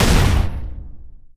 PNRoyalRifleSound.wav